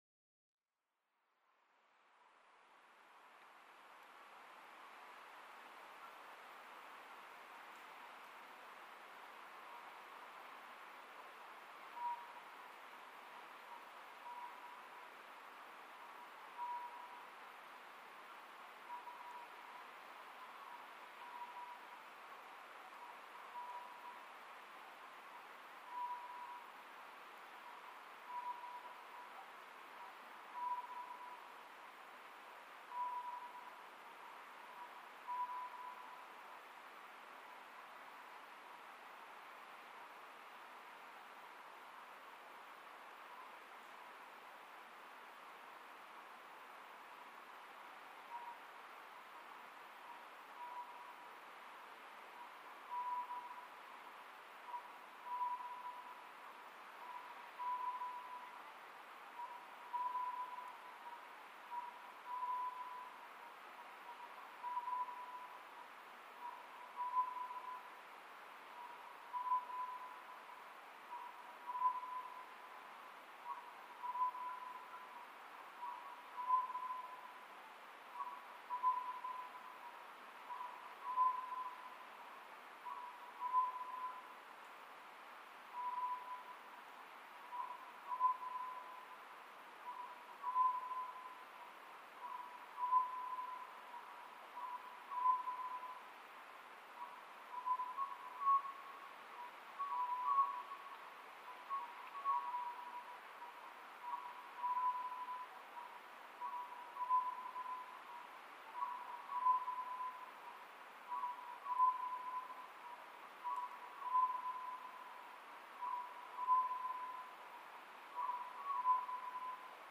コノハズク　Otus scopsフクロウ科
日光市砥川上流　alt=550m
Mic: Panasonic WM-61A  Binaural Souce with Dummy Head
二羽が鳴いているように聞こえます。